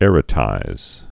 (ĕrə-tīz)